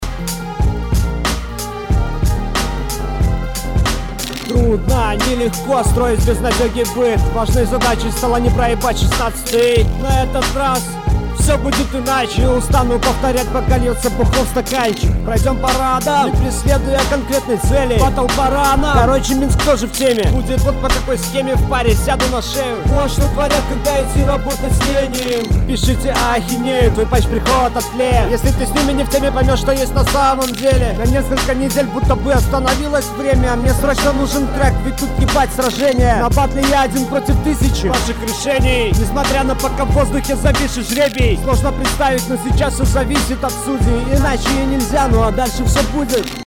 В целом исполнение монотонное, текст тоже средний, водянистый.